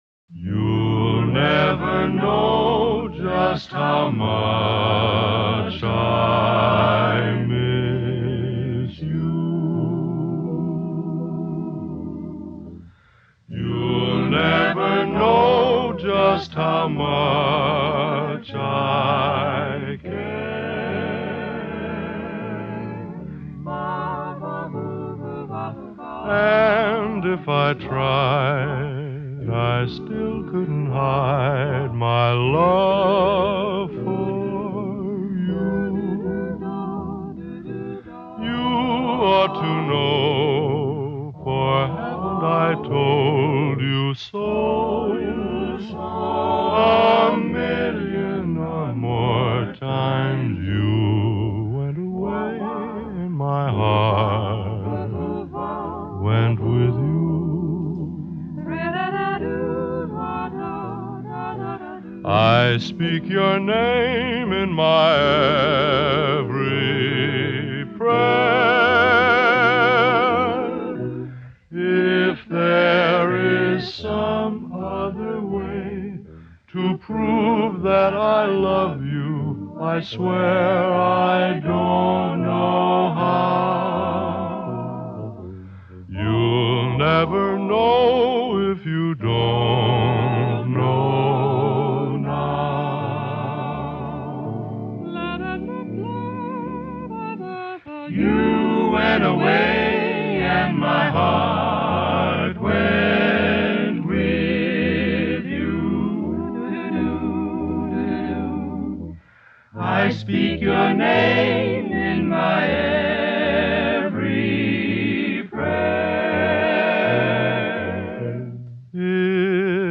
Обладатель баритона красивого глубокого тембра.